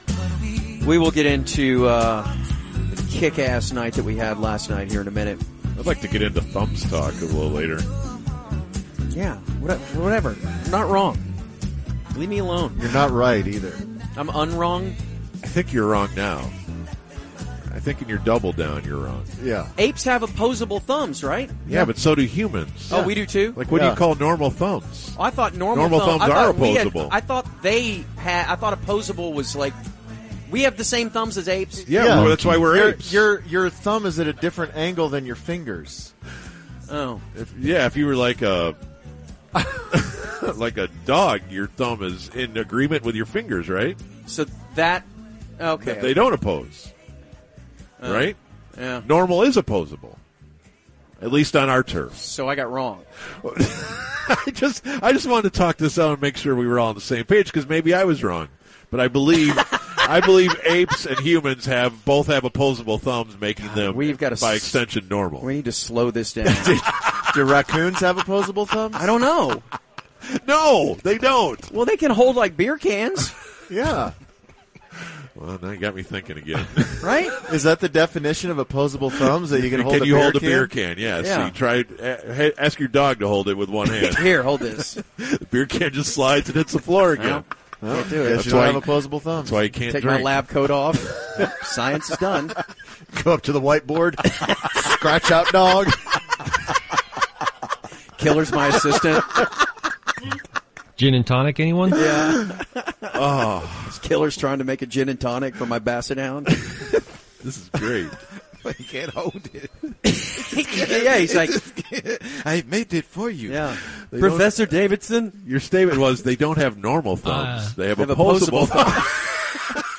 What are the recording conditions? Thumb talk and flubs in live spots.